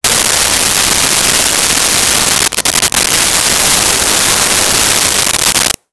Shower On
Shower On.wav